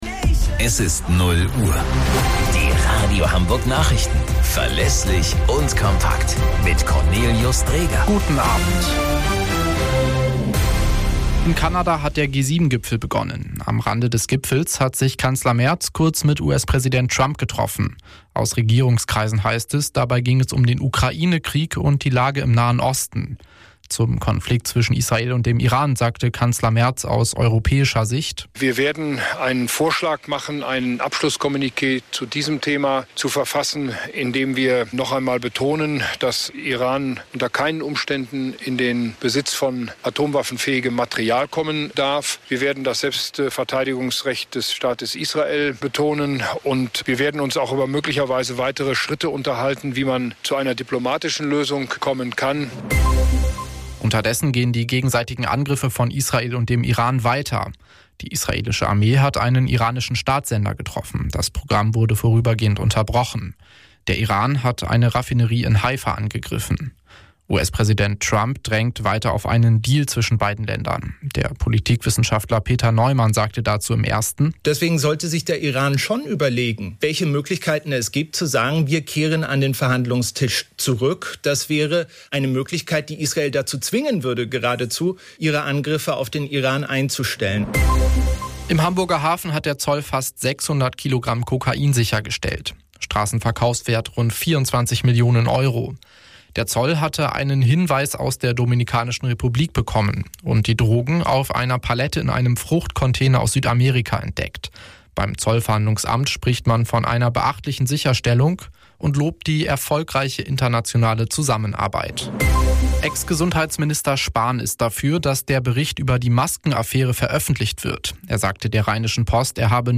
Die Radio Hamburg Nachrichten hört ihr nicht nur immer zur vollen Stunde, und morgens auch um Halb, bei uns im Programm, sondern auch auf eurem Smartphone, Tablett & Co. Denn alle wichtigen News aus Hamburg, Deutschland und der Welt gibt's gebündelt und kompakt für euch als Podcastangebot.